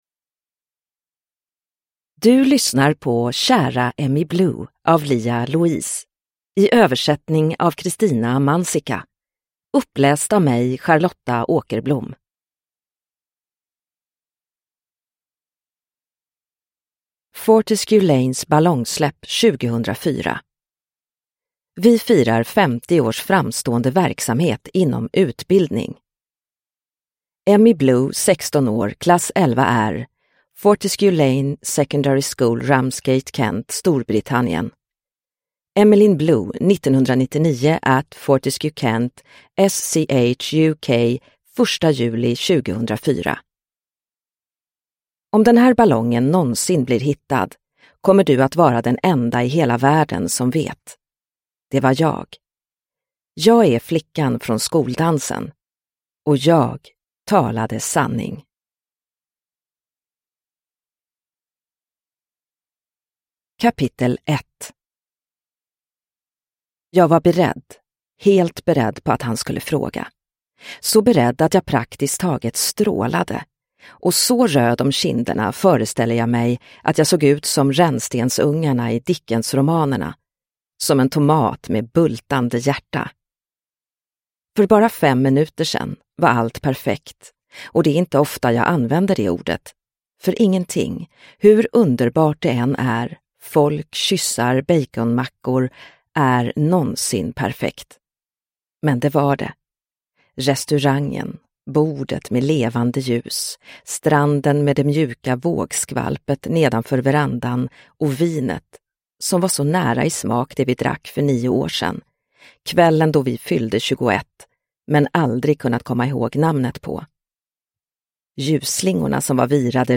Kära Emmie Blue – Ljudbok – Laddas ner